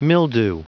Prononciation du mot mildew en anglais (fichier audio)
Prononciation du mot : mildew